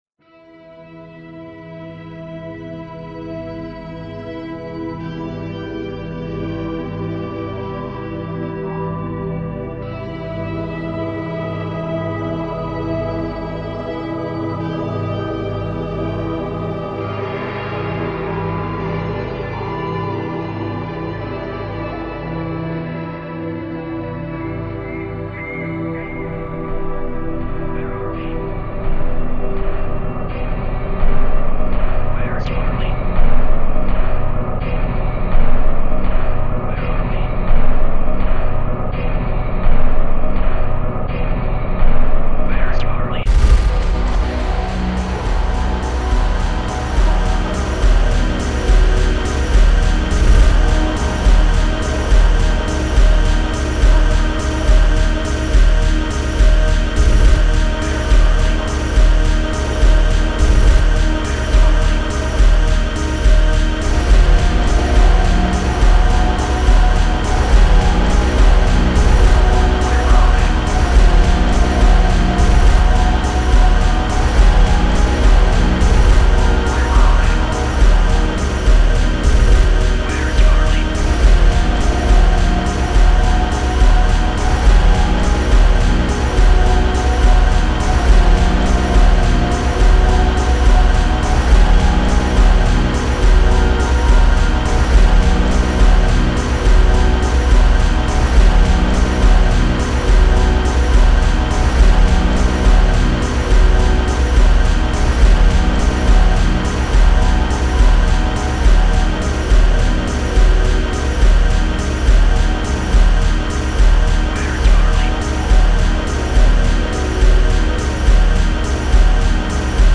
Waltz In Black - Sub Heavy Tech